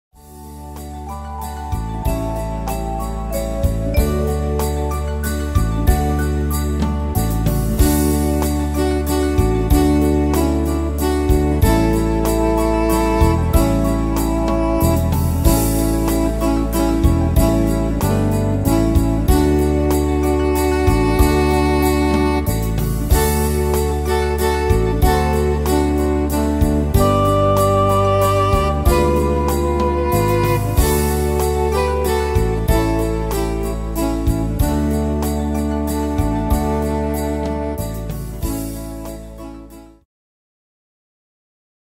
Tempo: 94 / Tonart: D-Dur